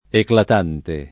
éclatant [fr. eklat3^] agg.; pl. m. éclatants [id.] — francesismo per «clamoroso»; a volte adattato, con poco rispetto della diversa struttura (quanto a ecl- iniz.) delle parole it., in eclatante [